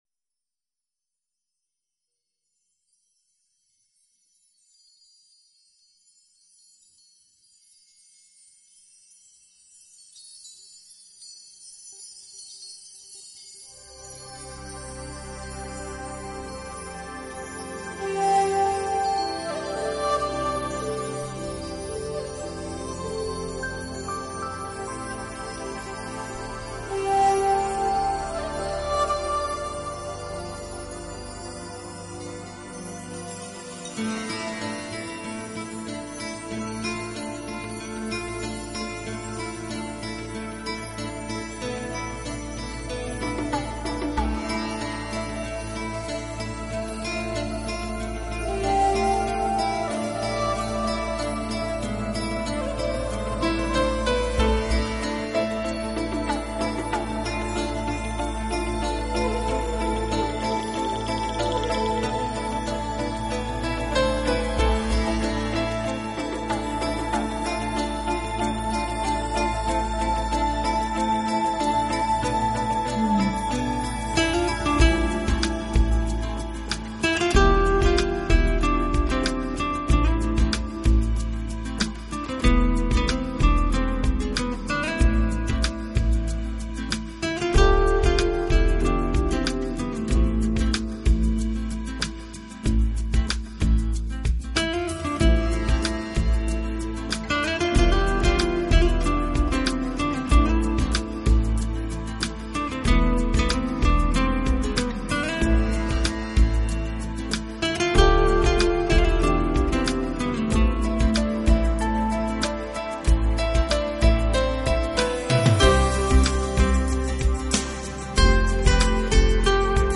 弱一点，但更显轻快平和，象天边的那一抹微云，悠远而自在。